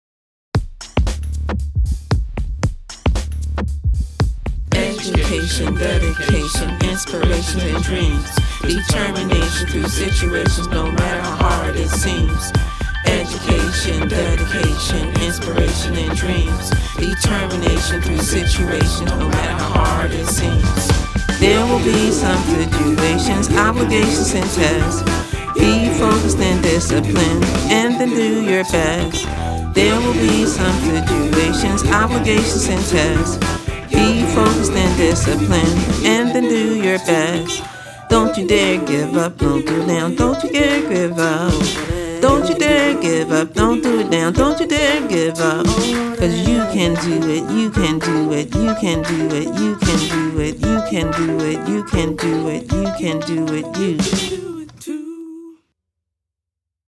Then they used Logic Pro X, microphones, and the digital audio interface to record themselves.
For inspiration, the class listened to children’s show theme songs.